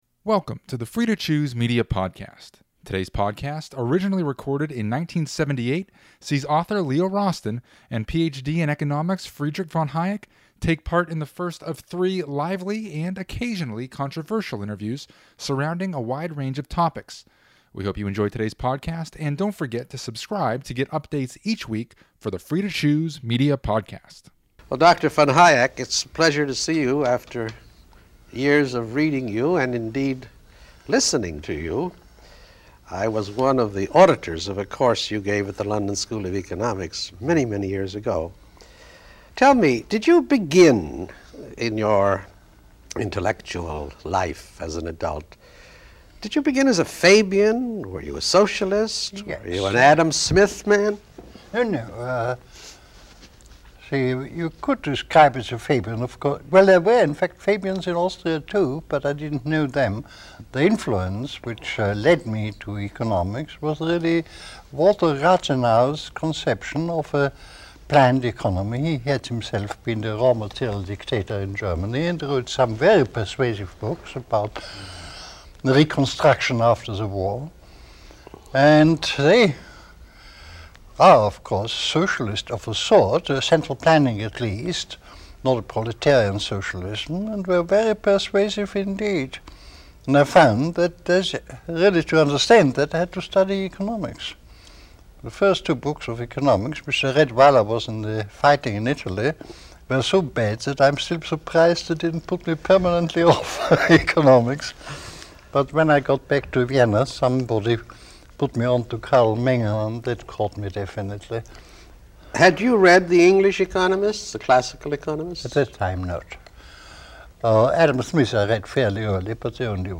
Listen to hour one of a lively and occasionally controversial three-part interview of noted economist Friedrich von Hayek by author Leo Rosten, in a wide-ranging discussion of von Hayek's life and work. Originally recorded back in 1978.